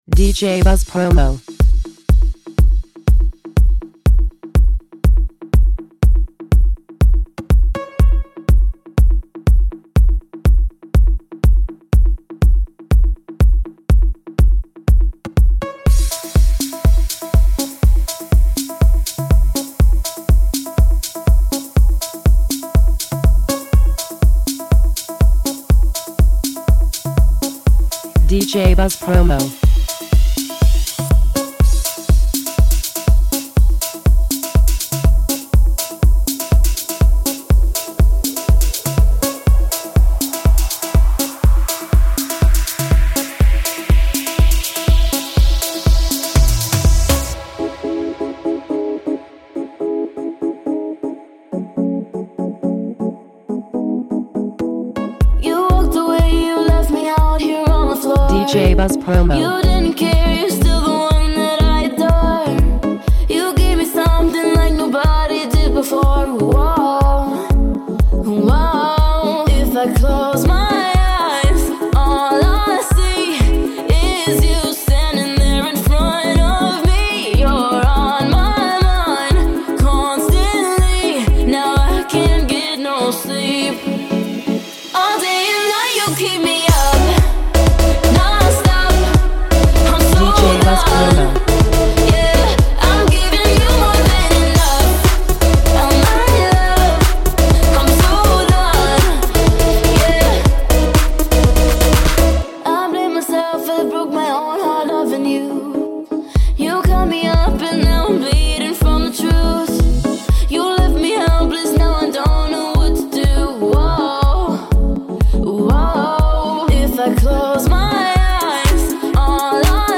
Extended